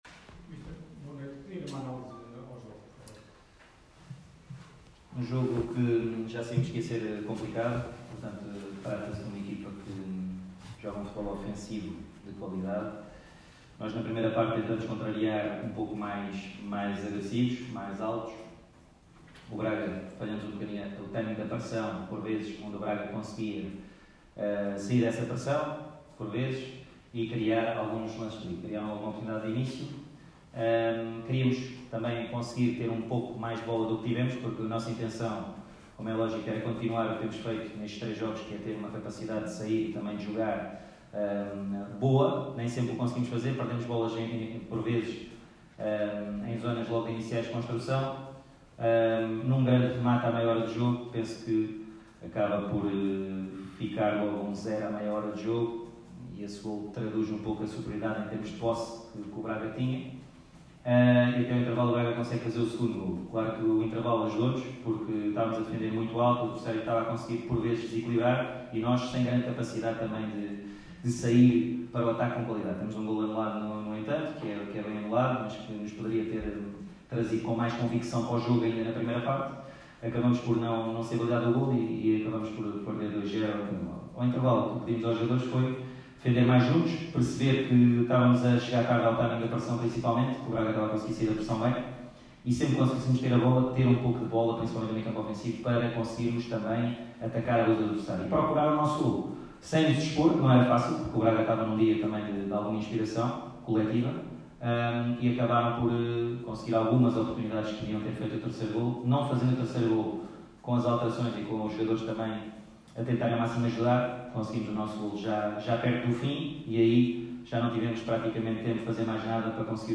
Na conferência de imprensa realizada no final do encontro